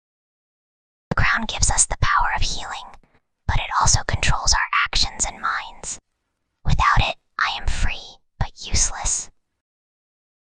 Whispering_Girl_31.mp3